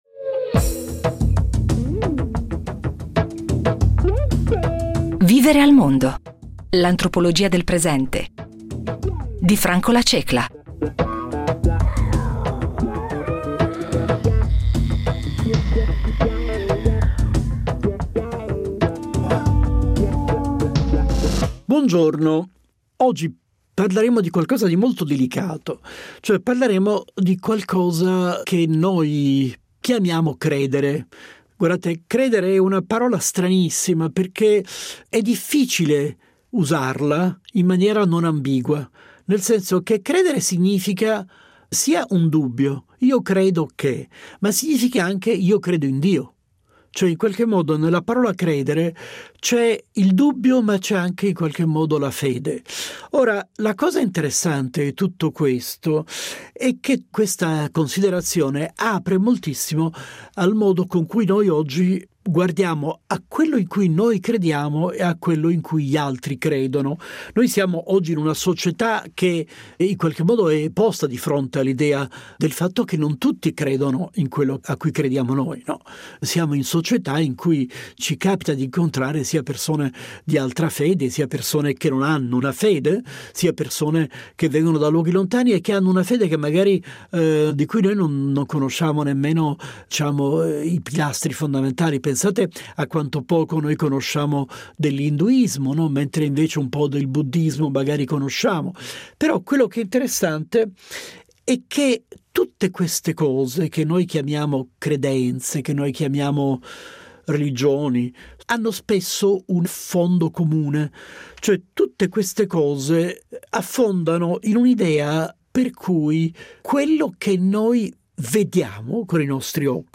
L’antropologia del quotidiano è un approccio che si concentra sullo studio della vita di tutti i giorni, analizzando come le persone vivono, interagiscono e danno significato al mondo che le circonda. Ci accompagna alla sua scoperta Franco La Cecla, antropologo di fama mondiale, che ha pubblicato più di 30 saggi, tradotti in molte lingue.